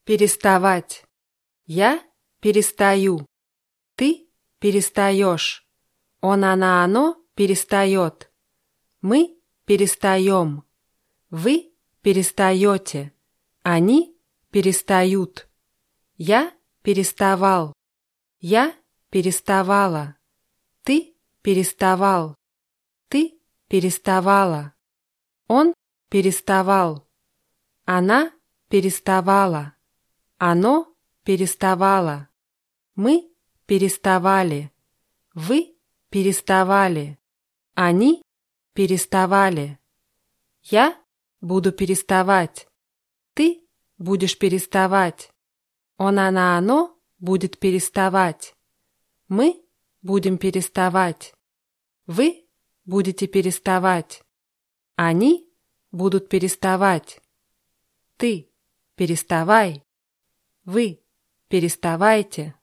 переставать [pʲirʲißtawátʲ]